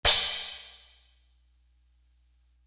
Crashes & Cymbals
Mpk Crash.wav